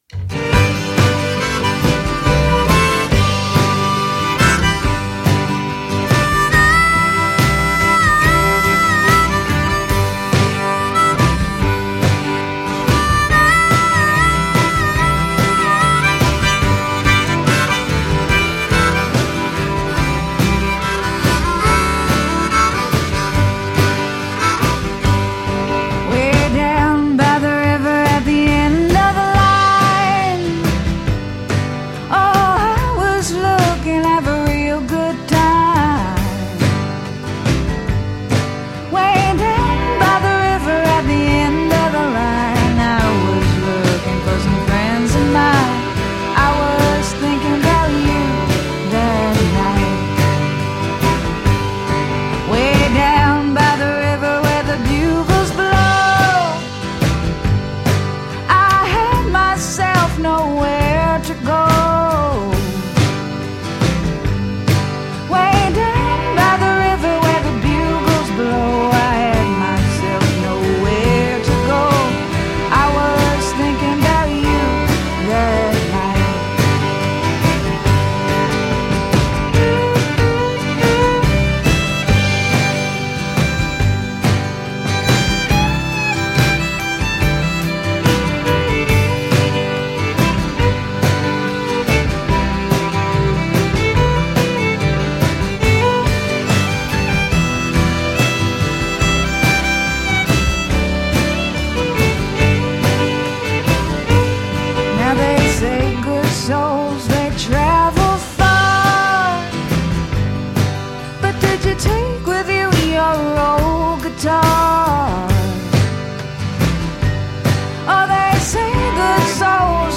The recording even sounds like it was done analog.